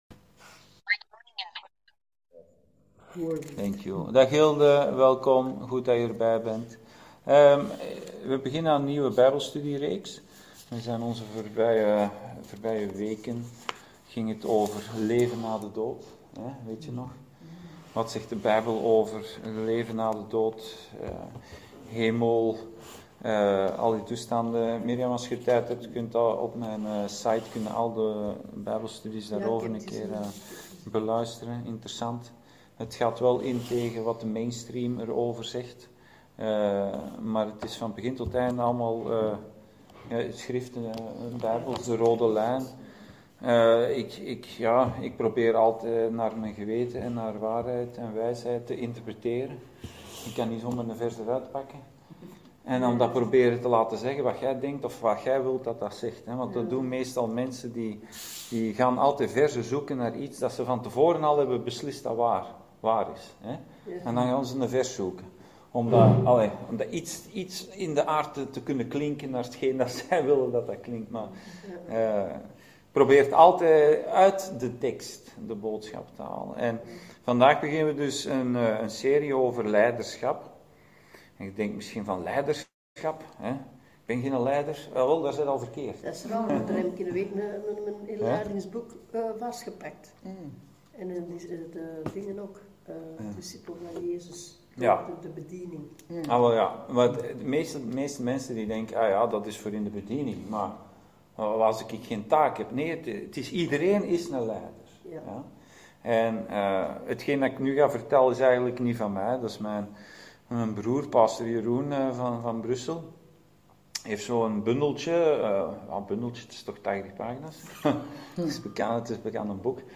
Bijbelstudie: Leiderschap – deel 1